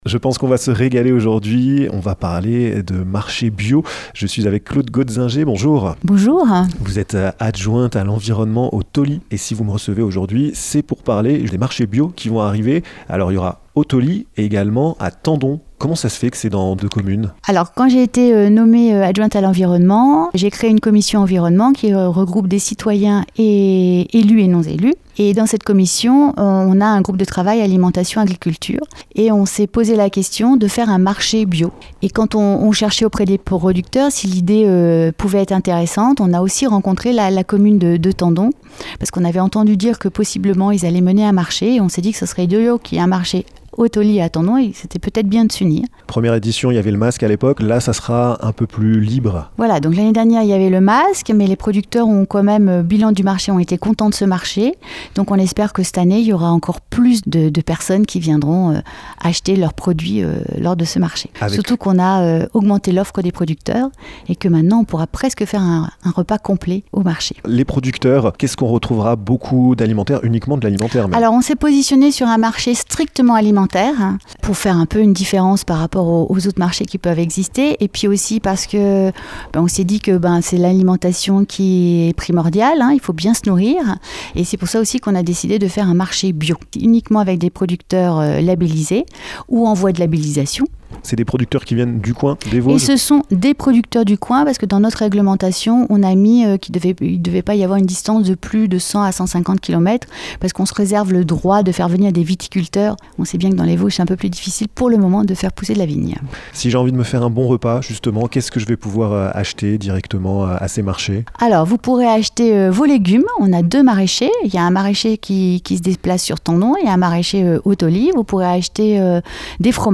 %%La rédaction de Vosges FM vous propose l'ensemble de ces reportages dans les Vosges%%
Les producteurs locaux vous attendent nombreux chaque 1er samedi du mois à Tendon et chaque 3e dimanche du mois au Tholy! Cleude Goetzinger, adjointe à l'environnement au Tholy, vous donne tous les détailles!